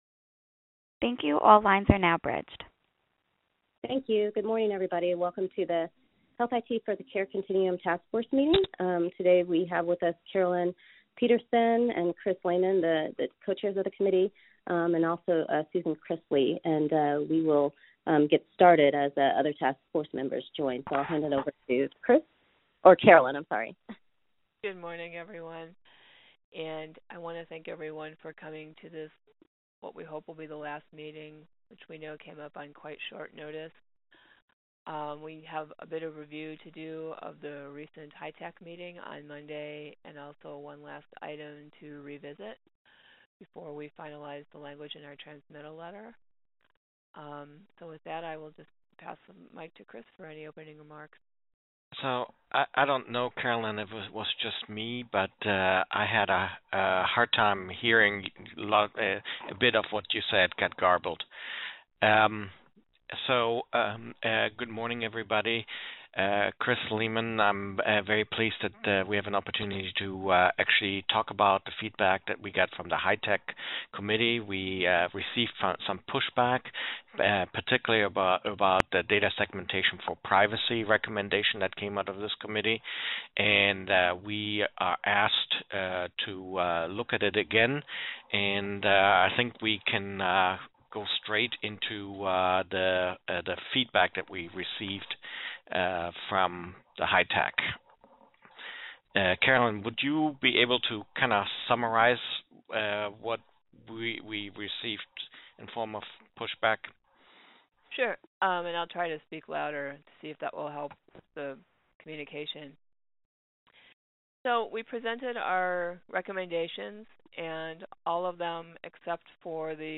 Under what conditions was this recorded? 2019-05_17_HITCC_VirtualMeeting_Audio